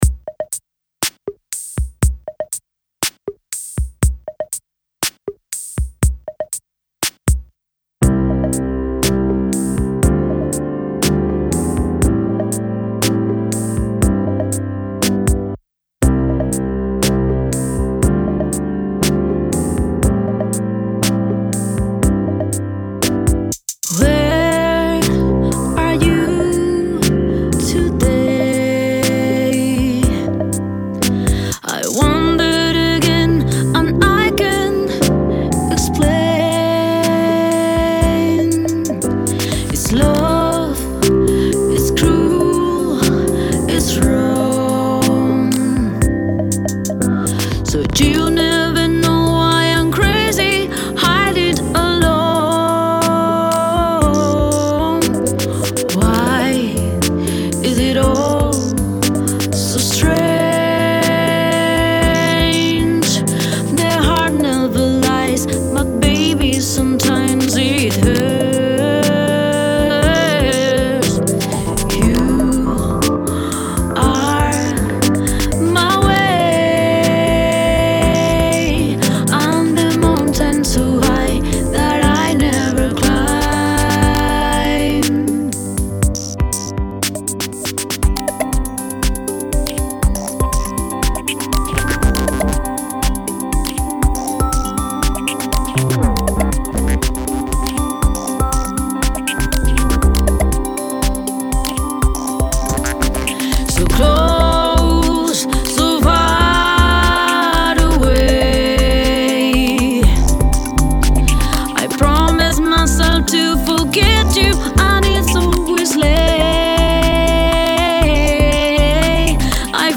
pianö, synth bass, prögramming, drum machines, loops
Genre Rock